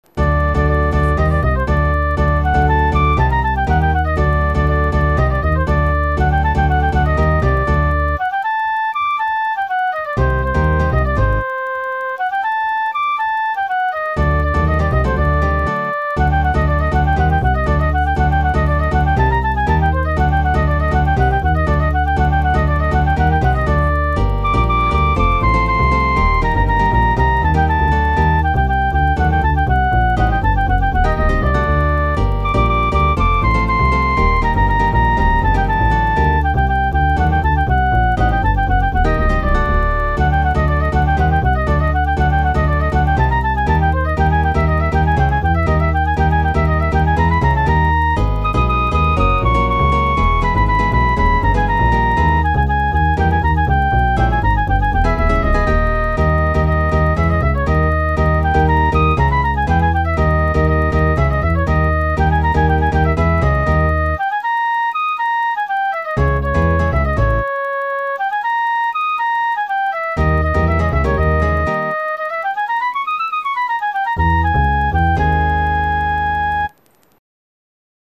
כתבתי את זה על המחשב - כך שהצליל לא משהו.
כתבתי את הכל על המחשב, הכלי הראשי אמור להיות חליל, האקורדים אם איני טועה אמורים להיות בצליל של גיטרה.
אלו הצלילים של הMIDI של הWindows אצלי.